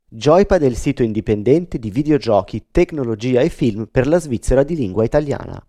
Per quanto riguarda il microfono, qua sotto trovate tre esempi, il primo usando il microfono delle Delta II, il secondo usando il leggendario Shure SMB7 e la terza il ROG Carnyx, microfono USB da stramer di ASUS.